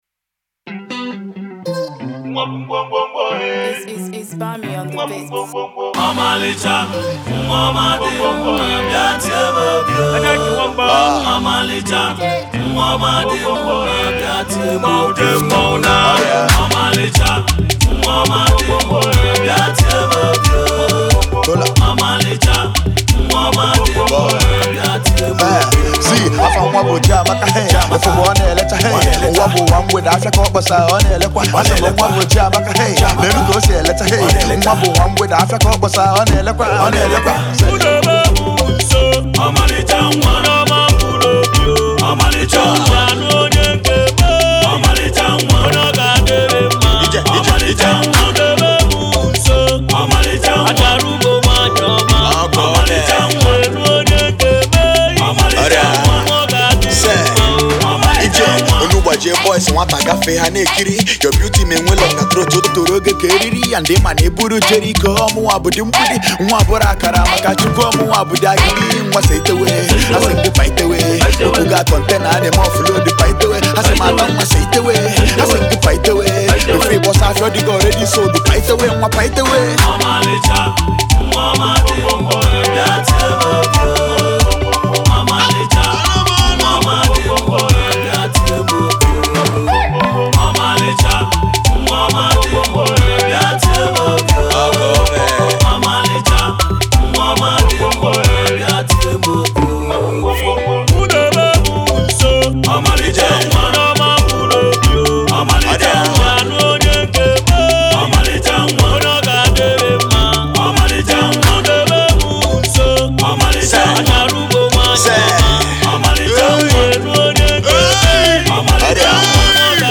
Hot indigenous rapper disturbing the East